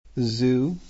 Irisch-Englisch